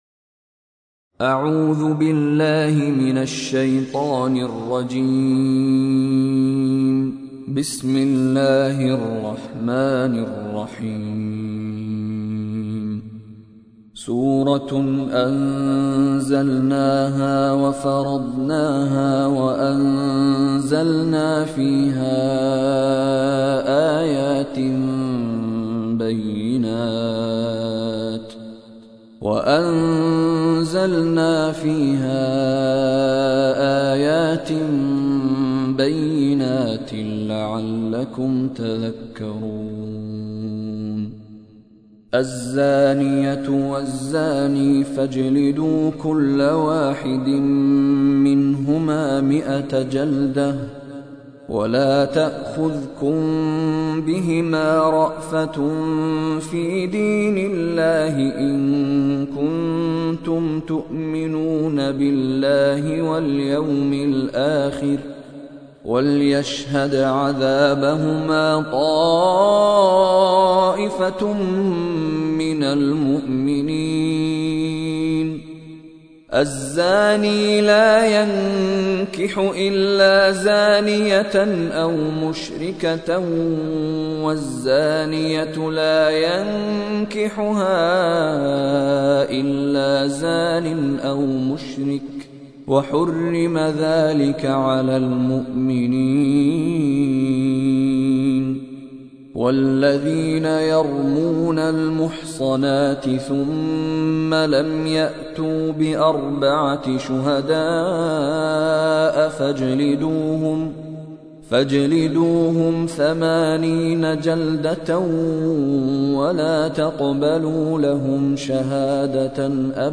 কোরআন তেলাওয়াত